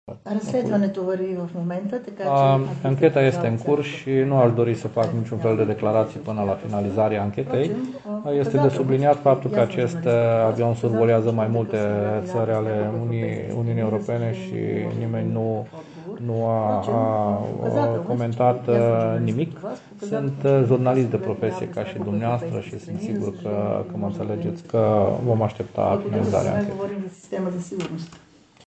Și situaţia avionului misterios care a survolat mai multe ţări din zonă  a fost ridicată de jurnalişti la vizita vicepreşedintelui Bulgariei în vestul ţării.